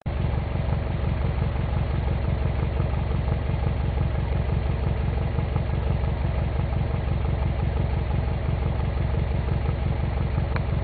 Normal MUFFLER アイドル時とRacing Titan アイドル時です。
多分、音質が違うせいであると思われるが、ガナドールマフラーは重低音がかなり効いたサウンドになる。
ボクサーサウンドが強調されるので、水平対向に乗ってる！って感じがする。